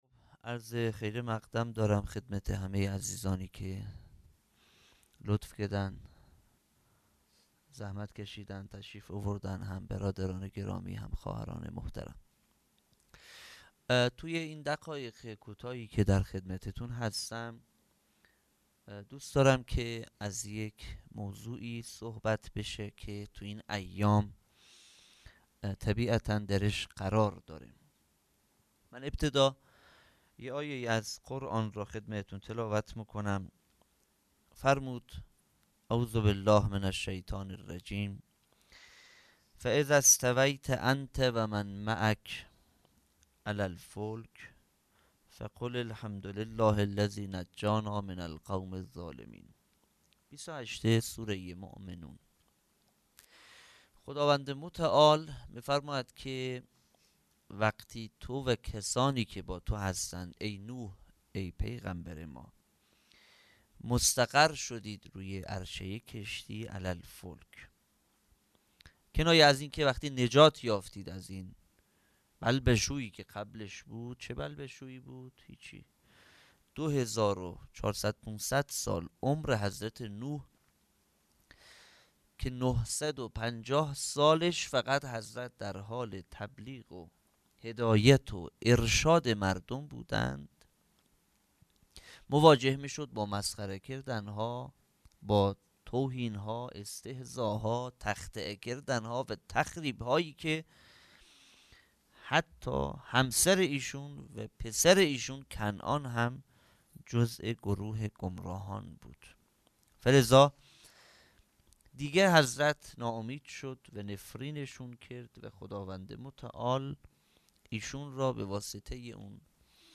خیمه گاه - هیئت بچه های فاطمه (س) - سخنرانی